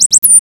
BIRD5.WAV